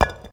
16. 16. Percussive FX 15 ZG